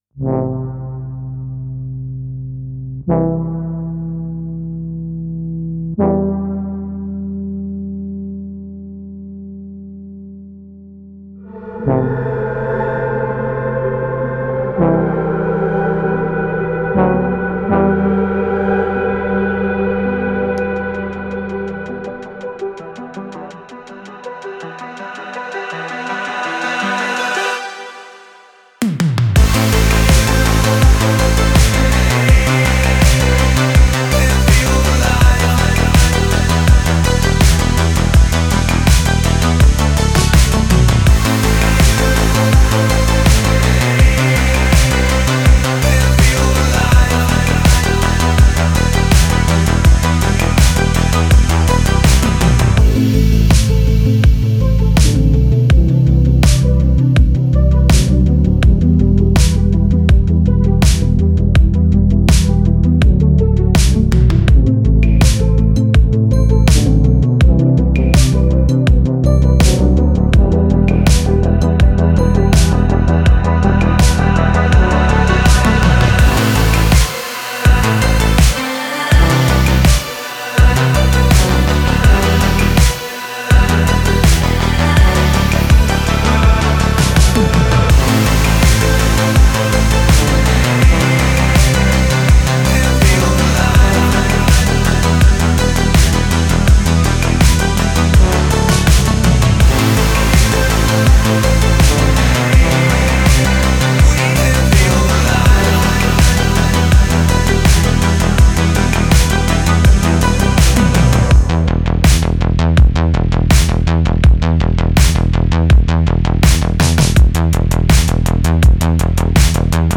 Synthwave Electro Pop